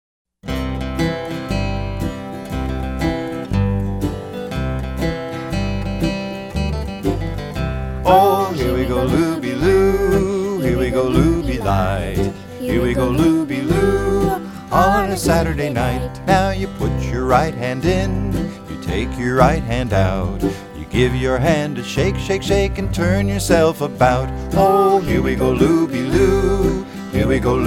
This collection of folk song favorites